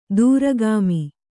♪ duragāmi